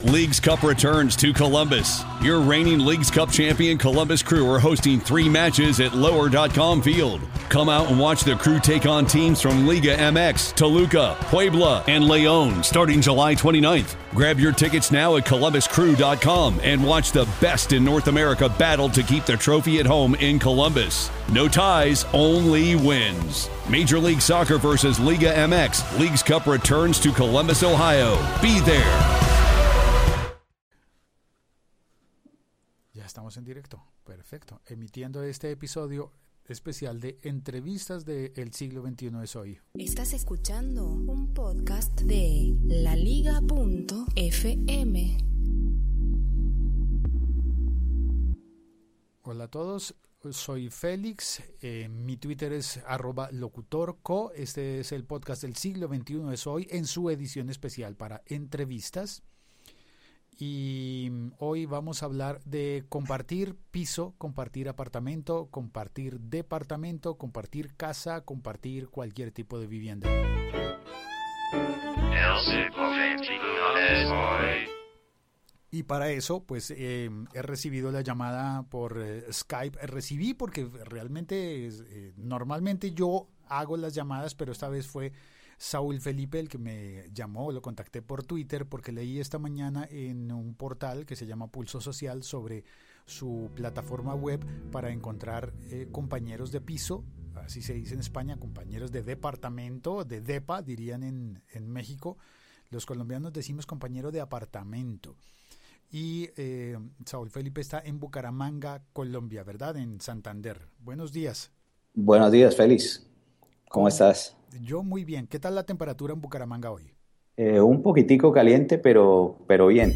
Entrevistas del Archivo / Compartir piso o apartamento